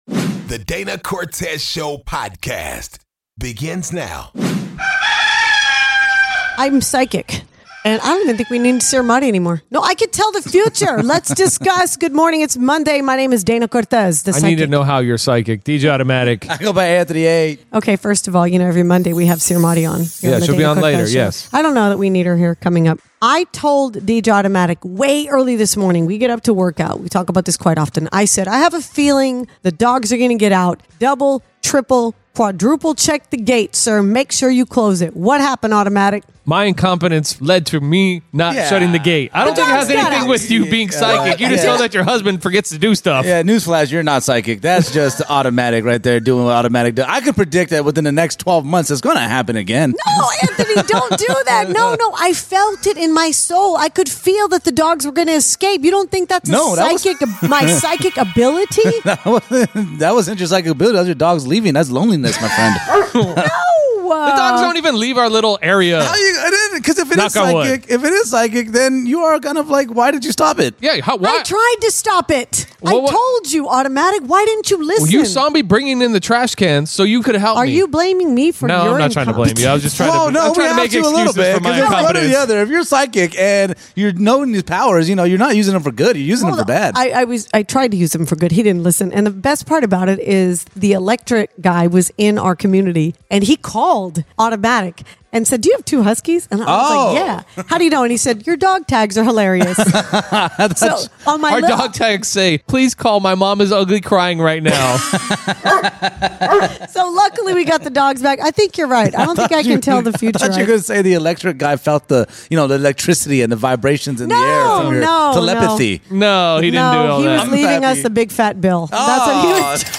A DCS listener called in to tell us about her weight loss journey and its amazing!